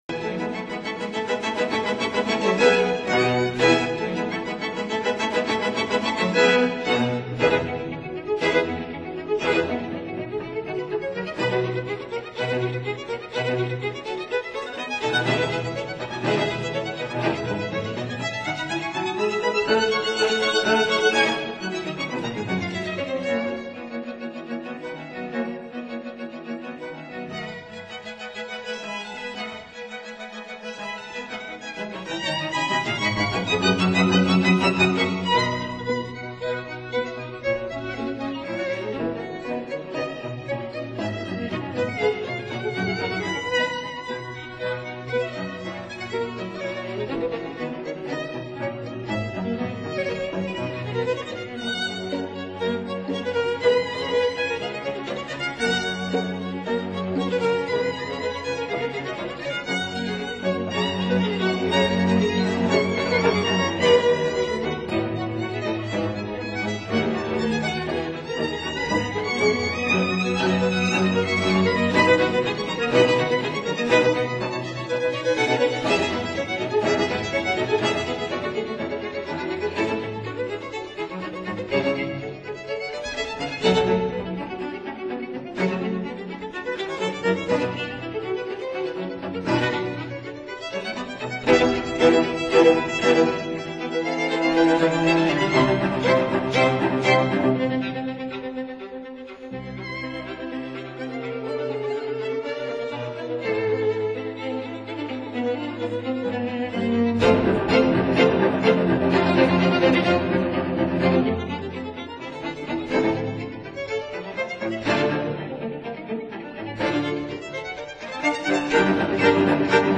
violin
viola
cello